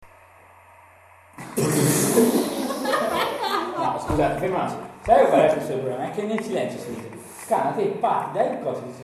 Risata
Risata.mp3